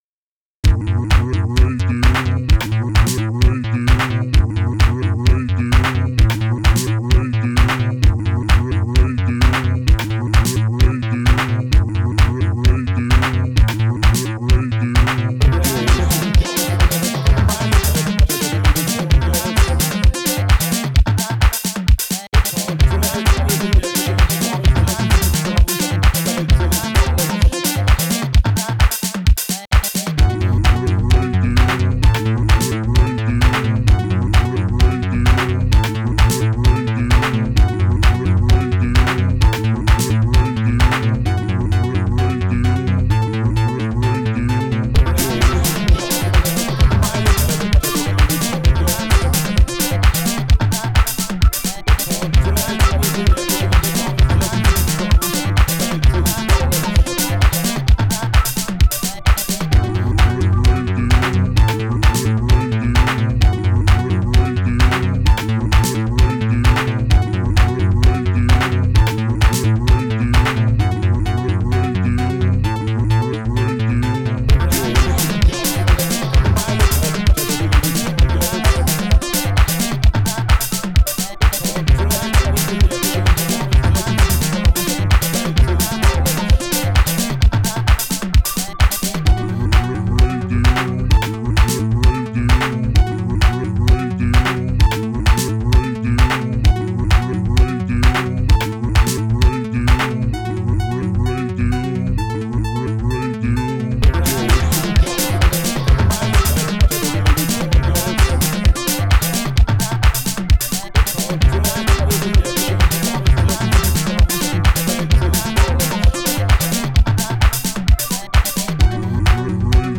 Genre: IDM, Minimal Wave, Synth-pop.